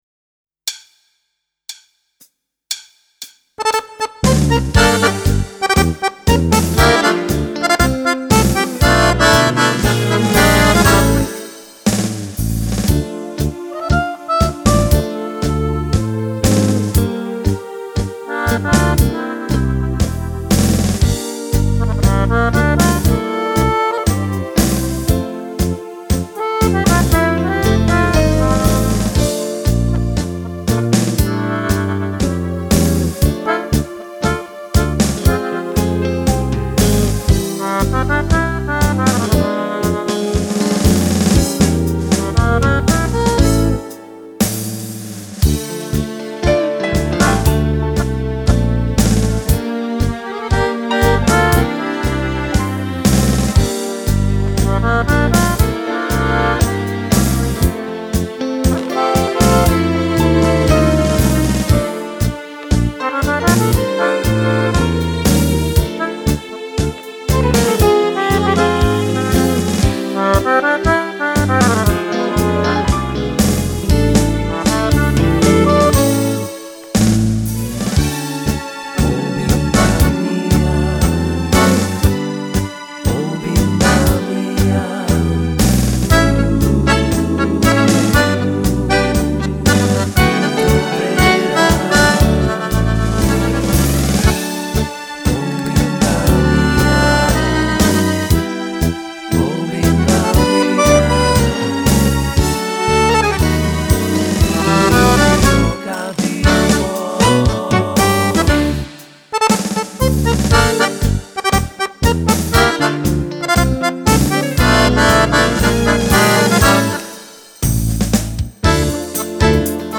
Tango
Donna